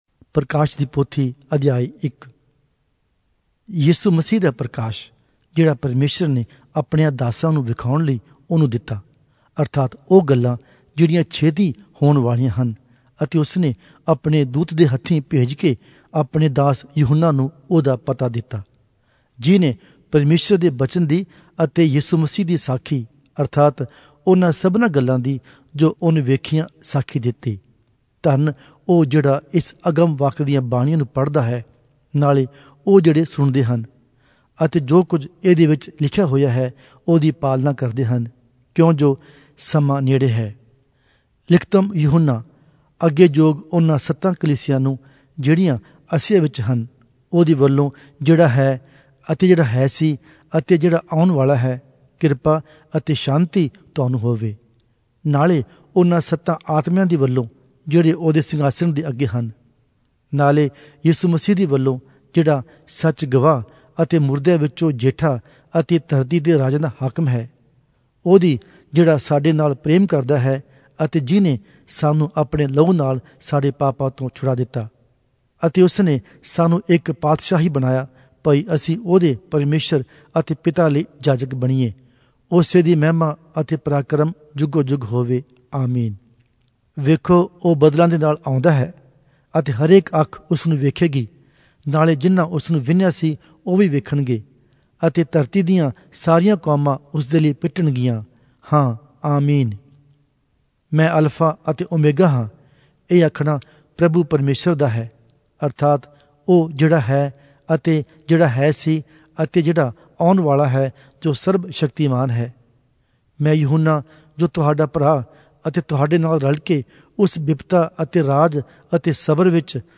Punjabi Audio Bible - Revelation 11 in Hcsb bible version